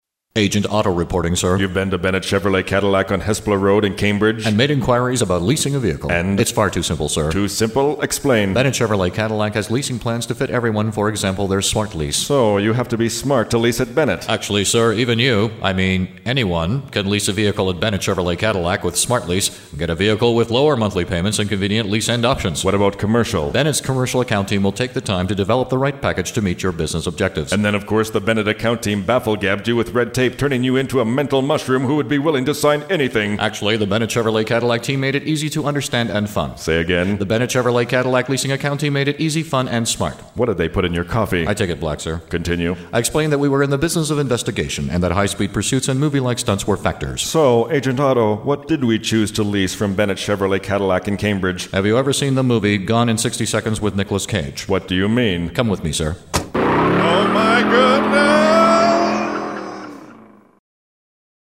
Agent Auto samples feature complete 60 second commercials and are the copyrighted material of Radio Production CA. Monthly Pricing is available for annual campaigns.